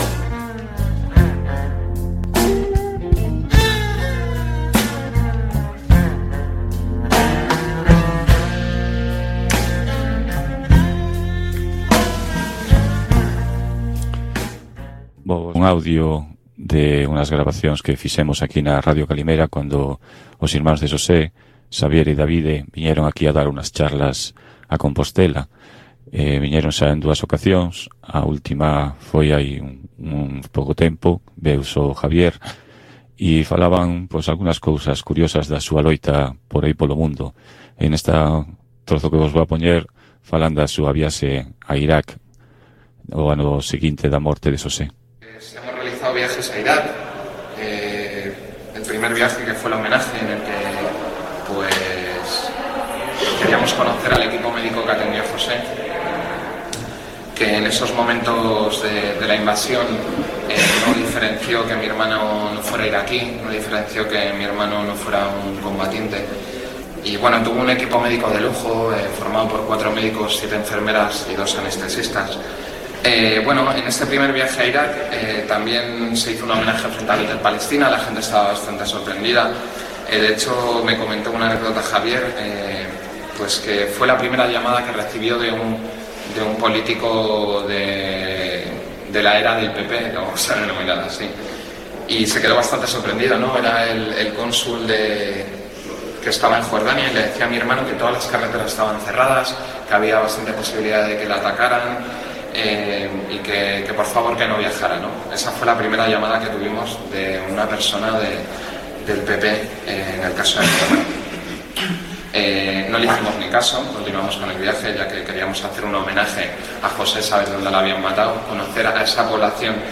Conexión en directo con Radio Pardiñas, a emisión radiofónica desde o Festival de Pardiñas (Pardiñas, Guitiriz, Lugo).